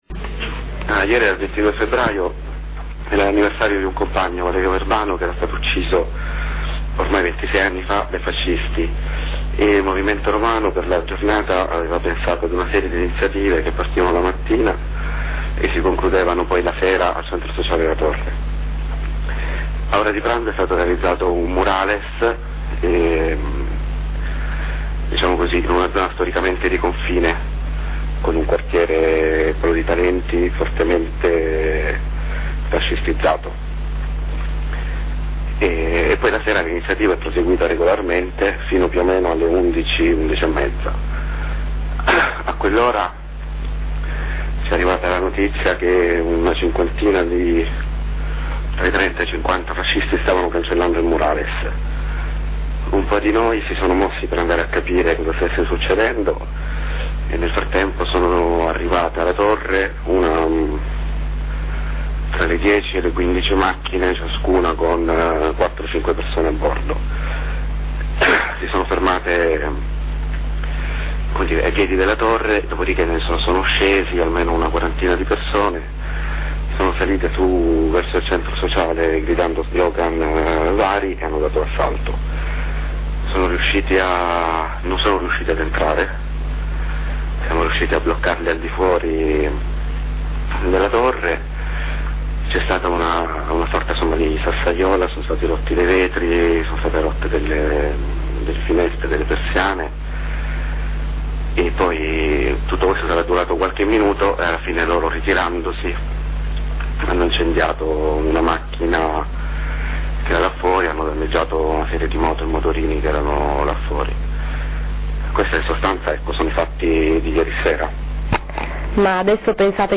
Ieri sera, alla fine della giornata di iniziative in memoria di Valerio Verbano, attacco fascista al CSA la torre, dove si teneva la serata conclusiva. Un racconto dei fatti da un attivista del centro.
stessa intervista in formato mp3